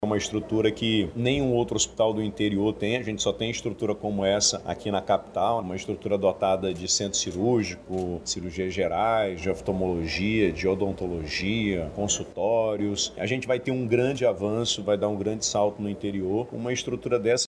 Durante a vistoria, o governador do Amazonas, Wilson Lima, destacou que a embarcação vai contar com uma estrutura superior à de hospitais do interior do Estado.